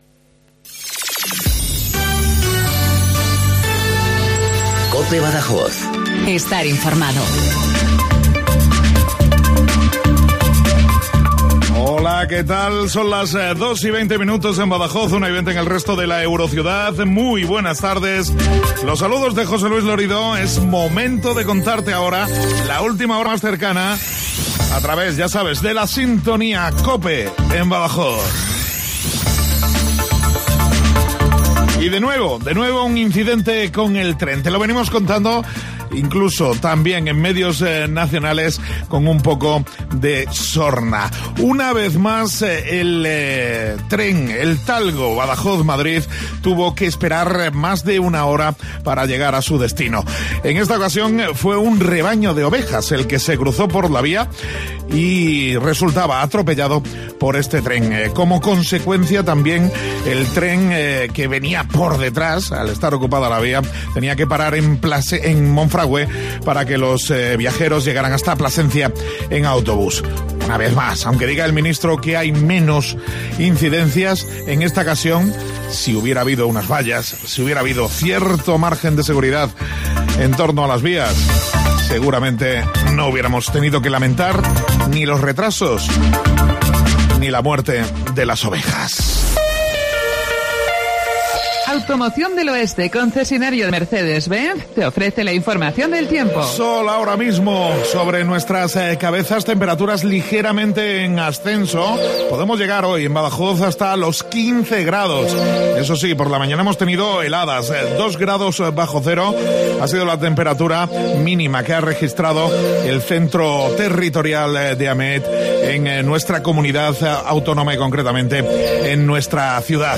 INFORMATIVO LOCAL BADAJOZ 1420